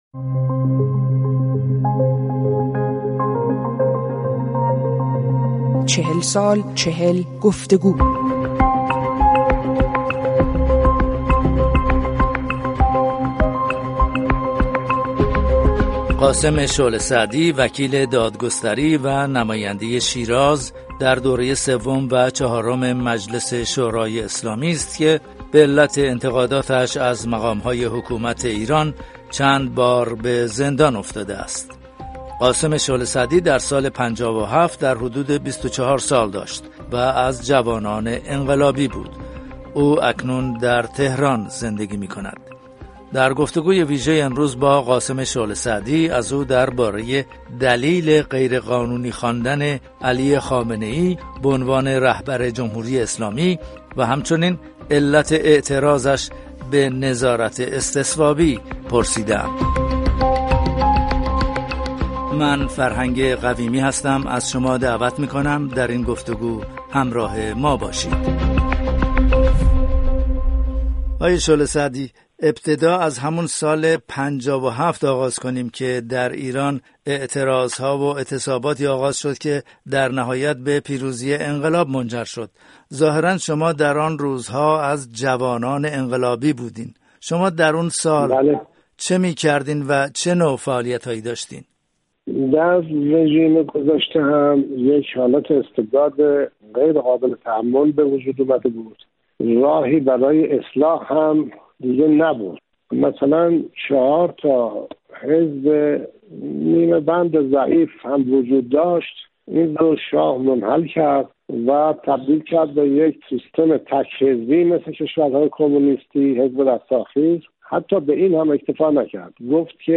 گفت‌وگو با قاسم شعله‌سعدی